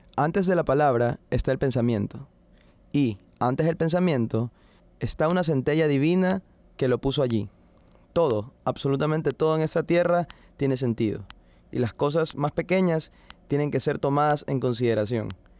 Restauración de voz deteriorada por ruido de pulsos
Nombre: voz con ruido.wav
voz con ruido.wav